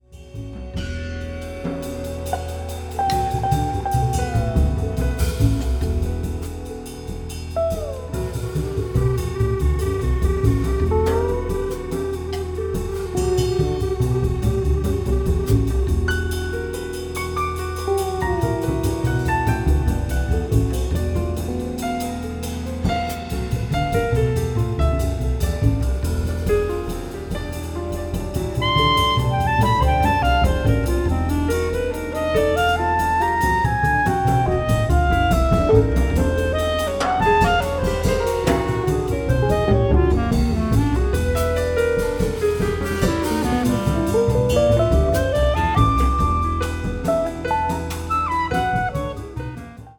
clarinet, bass clarinet, alto saxophone
electric guitar
Fender Rhodes, piano, synths
drums
acoustic and electric bass